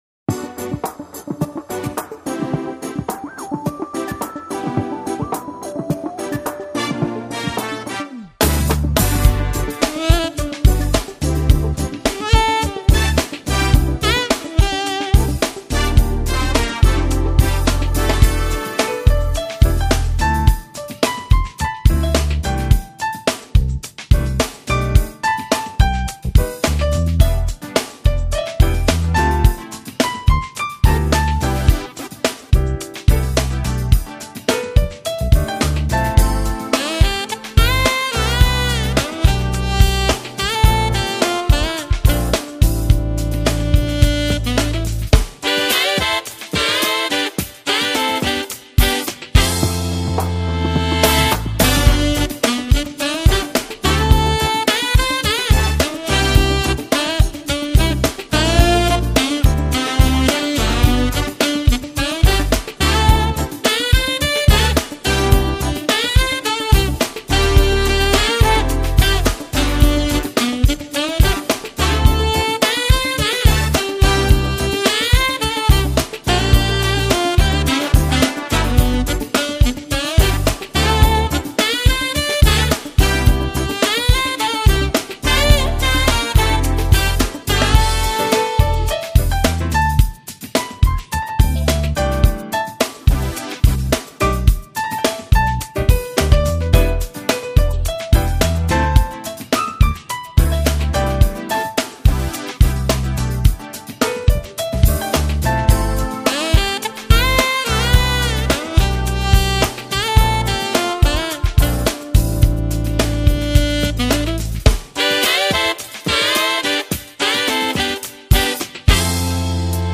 音乐风格： Jazz-Pop Smooth Jazz